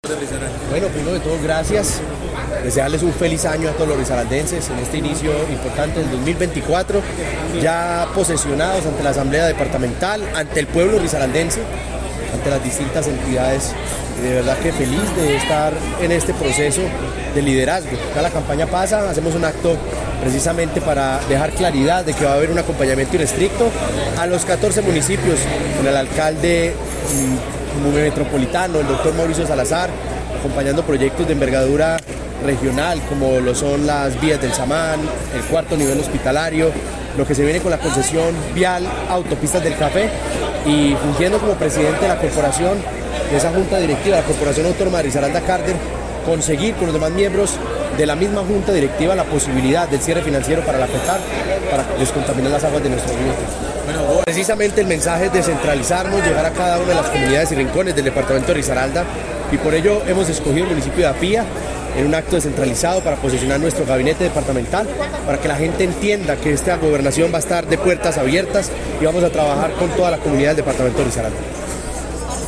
En medio de un emotivo encuentro en la Asamblea Departamental de Risaralda, el Gobernador Juan Diego Patiño Ochoa tomó posesión de su cargo ante los diputados, invitados especiales y medios de comunicación, que lo acompañaron en este importante evento.